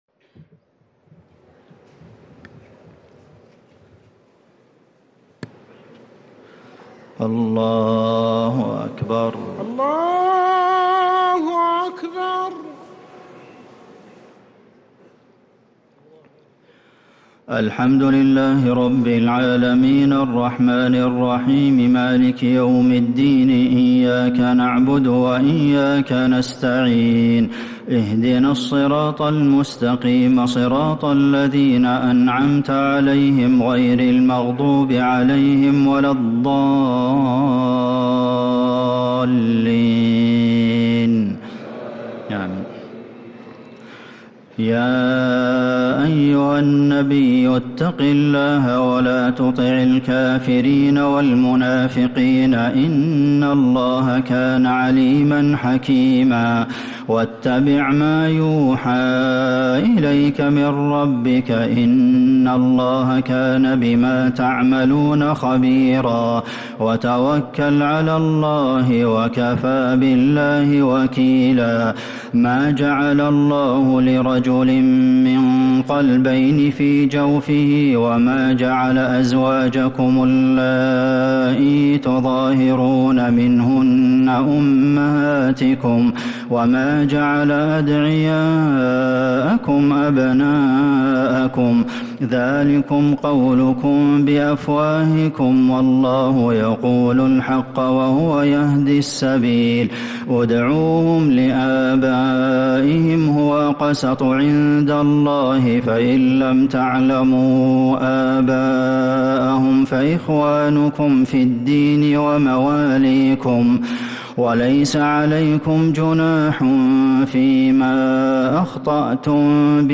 تراويح ليلة 24 رمضان 1446هـ فواتح الأحزاب (1-48) | taraweeh 24th night Ramadan 1446H surah Al-Ahzab > تراويح الحرم النبوي عام 1446 🕌 > التراويح - تلاوات الحرمين